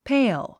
発音
péil　ペイル
pale.mp3